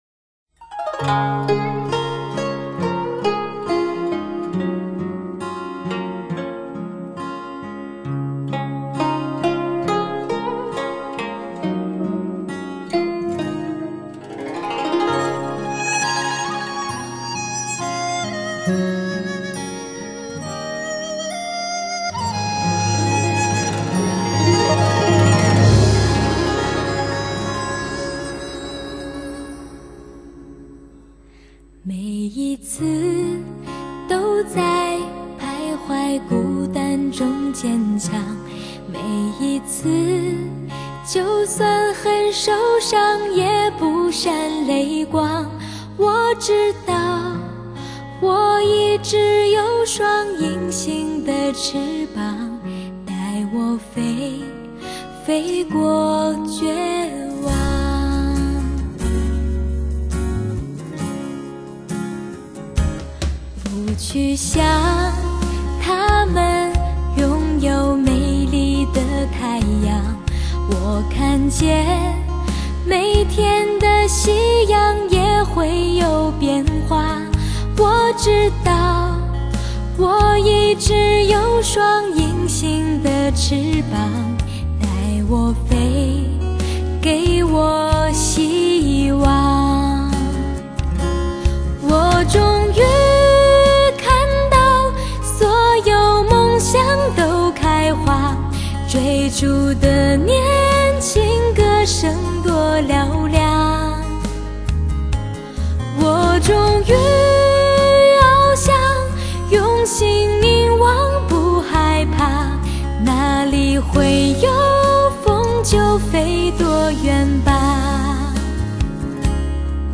DTS 5.1声道高传真立体环绕声，
高密度的声音充分还原录音现场，